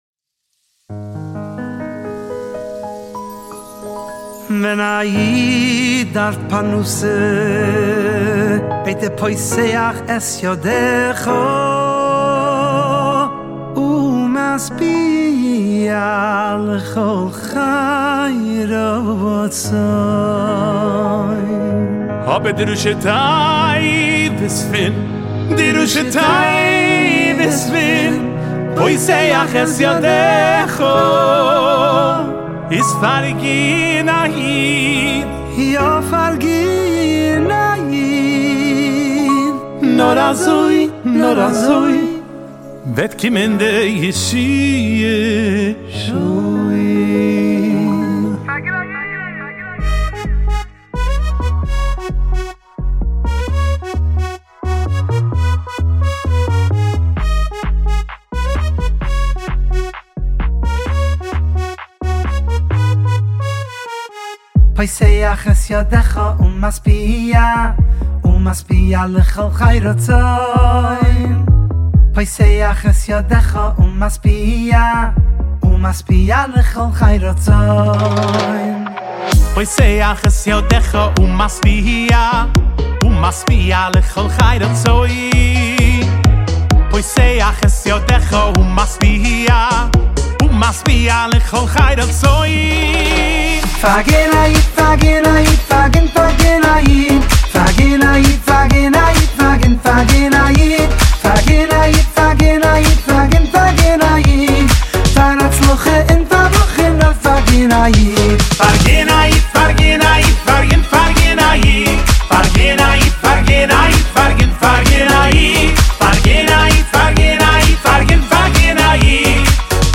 a fun packed song with a holy message